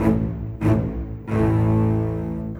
Rock-Pop 06 Bass _ Cello 01.wav